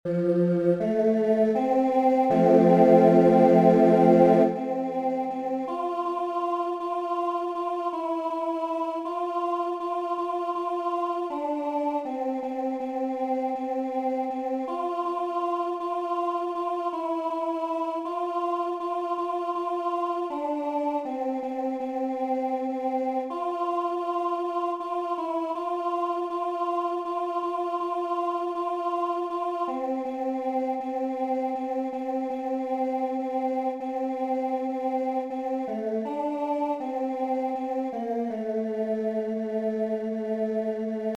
Alto Audio Part
Solid-Rock-Altos.mp3